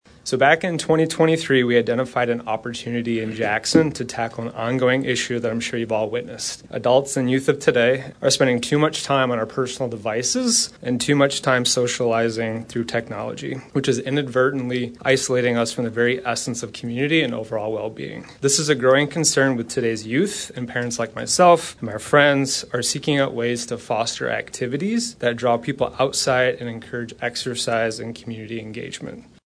Jackson, Mich. (WKHM) — A three-phase construction project to create recreational and community gathering spaces at Ella Sharp Park was approved by Jackson City Council during their meeting on Tuesday.